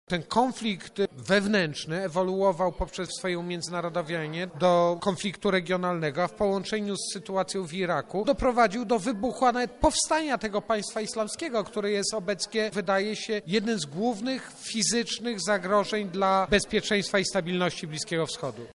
Konflikt Syrii jako zagrożenie dla bezpieczeństwa międzynarodowego. To temat konferencji naukowej jaka odbyła się na Wydziale Politologii UMCS.